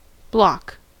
block: Wikimedia Commons US English Pronunciations
En-us-block.WAV